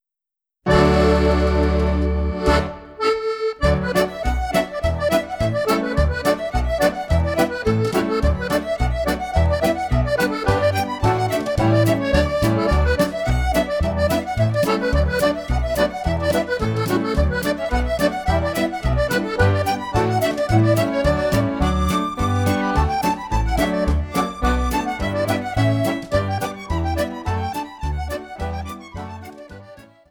Reel